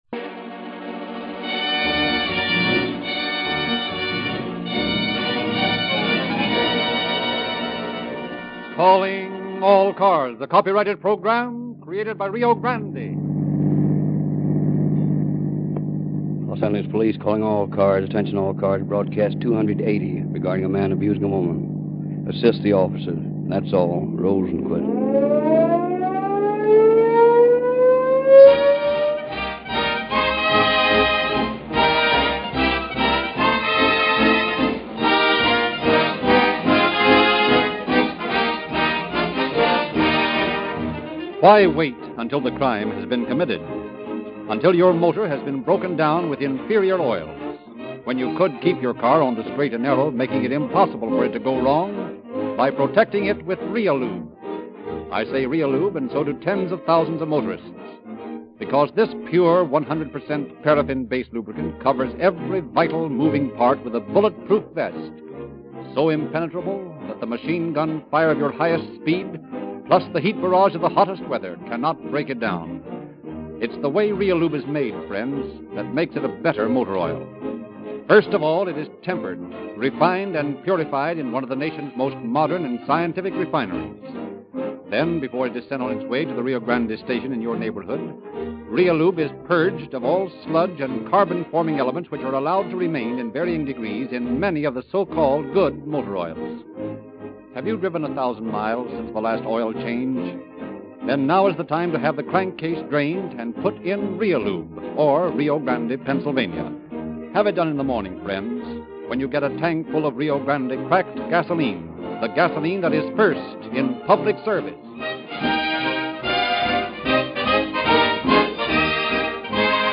Calling All Cars Radio Program